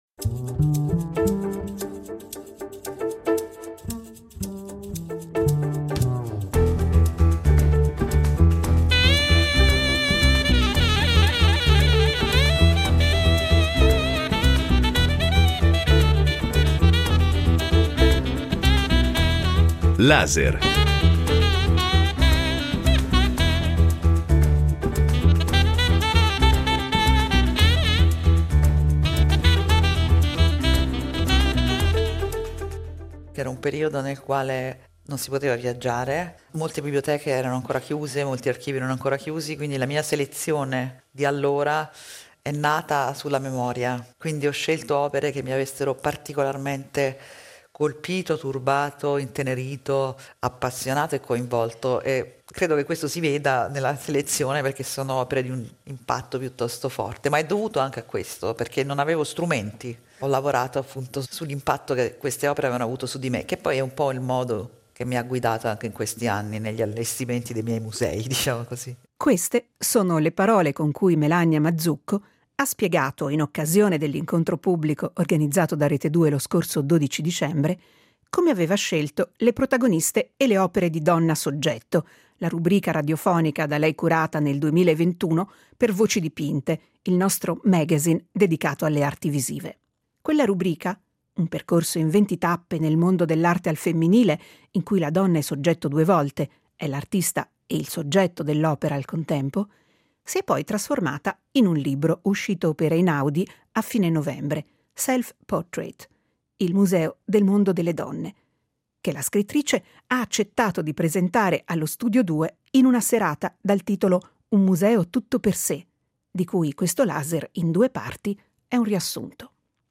Studio 2 - Lunedì 12 dicembre dalle 18.30 alle 20.00